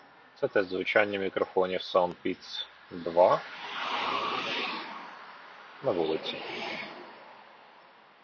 Мікрофон:
В гучних умовах: